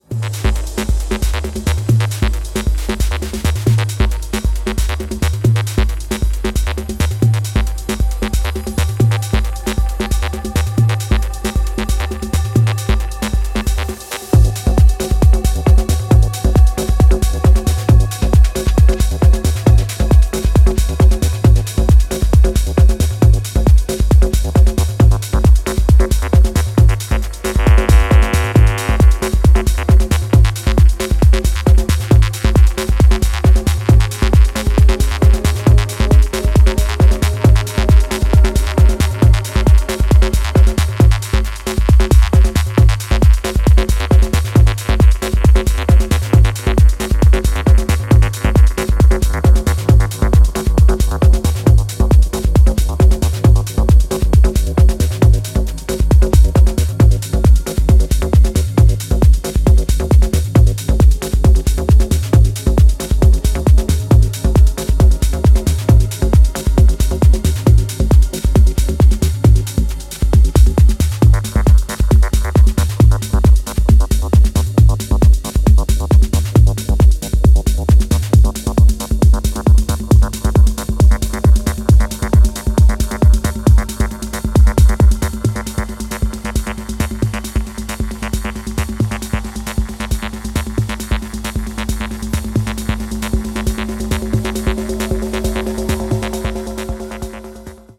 ディープ・テクノ推薦盤！